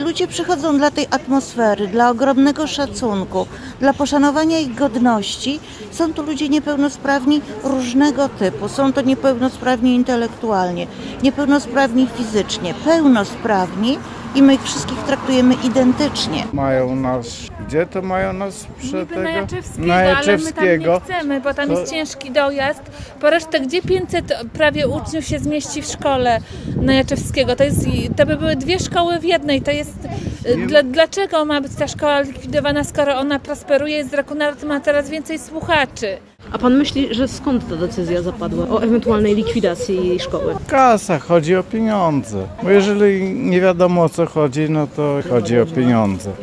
Protest przed urzędem marszałkowskim.
– Ludzie przychodzą dla tej atmosfery, dla ogromnego szacunku, dla poszanowania i godności – mówi jedna z protestujących.